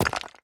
rock_destroyed_02.ogg